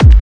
Kick g.wav